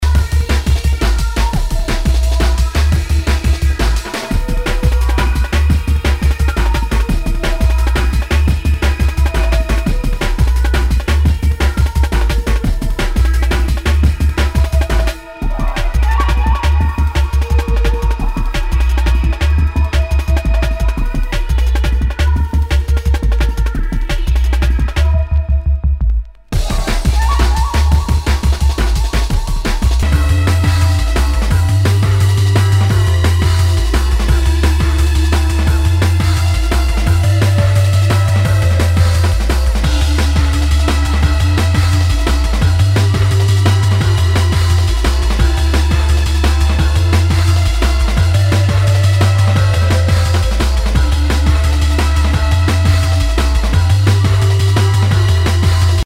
Nu- Jazz/BREAK BEATS
ナイス！ドラムンベース！